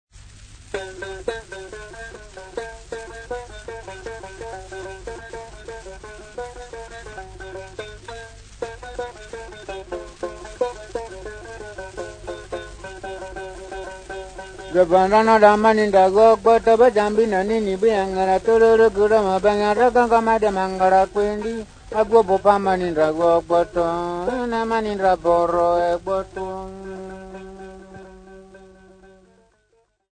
Folk Music
Field recordings
Africa Democratic Republic of Congo City not specified f-cg
sound recording-musical
Indigenous music